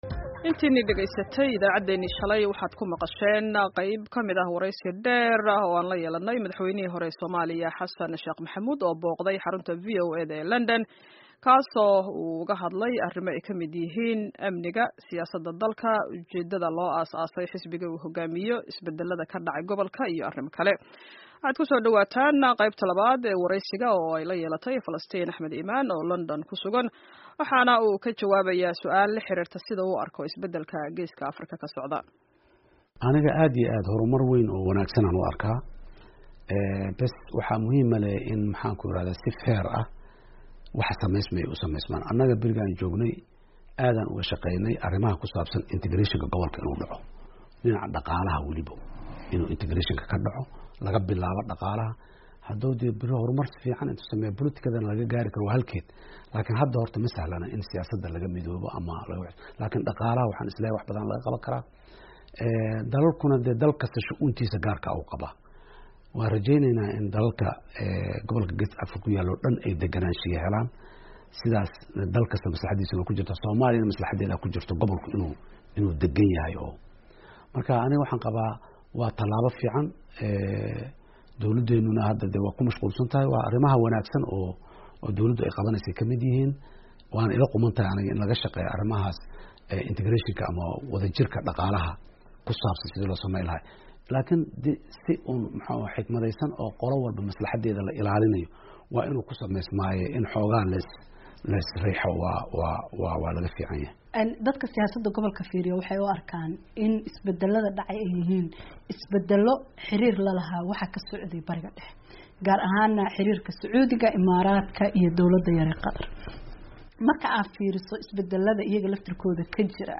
Qeybtii labaad wareysiga madaxweyne Xasan Sheekh